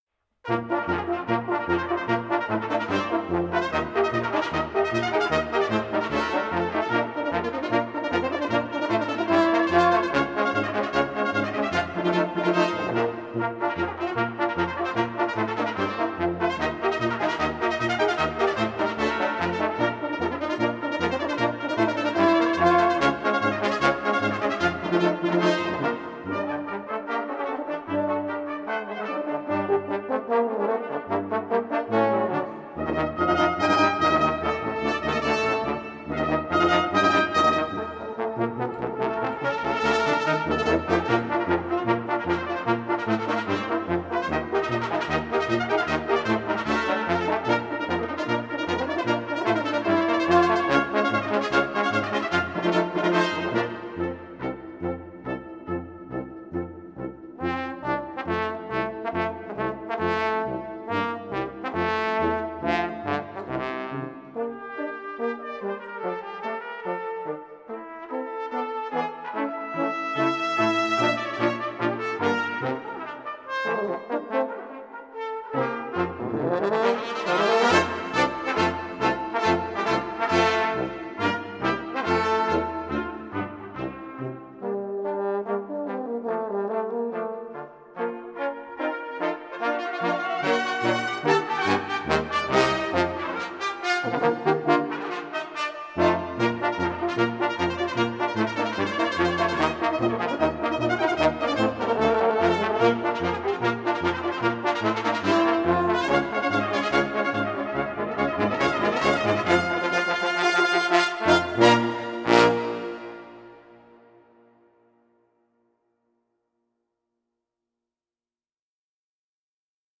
deepmp3-ru-white-nights-brass-quintet-saint-petersburg-carmen-suite-no-1-i-prelude.mp3